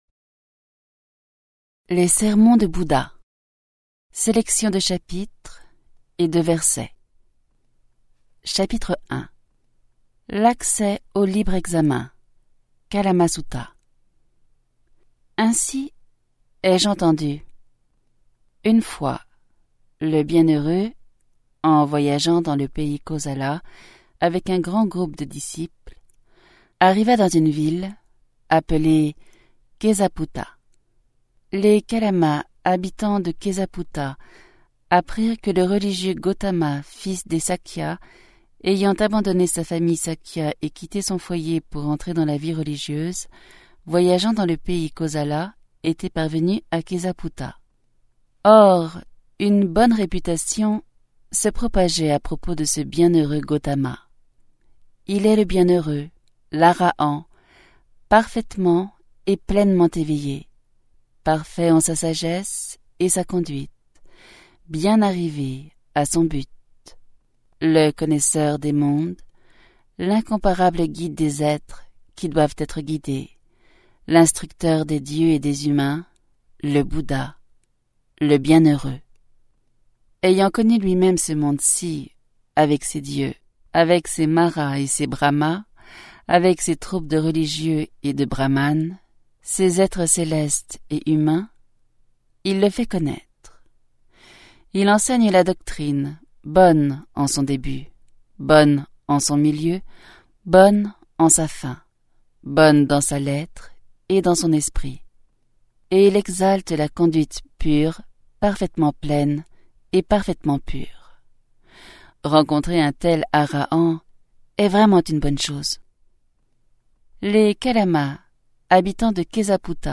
Narratrice